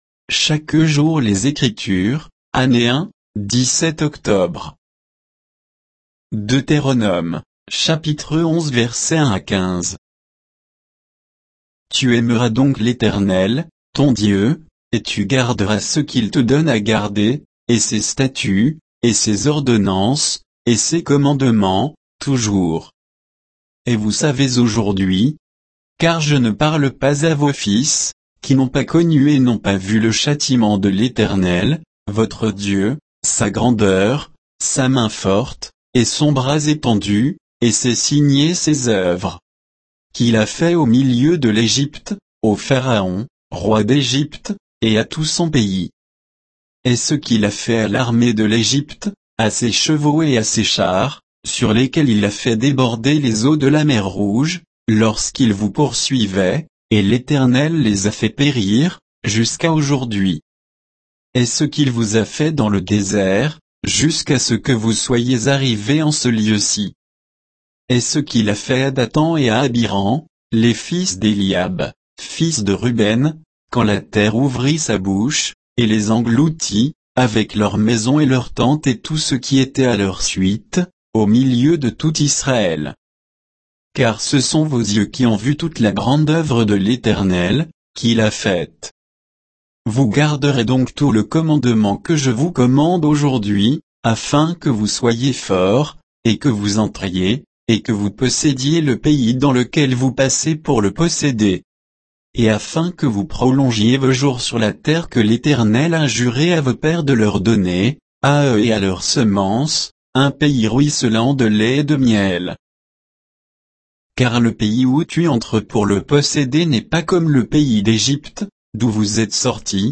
Méditation quoditienne de Chaque jour les Écritures sur Deutéronome 11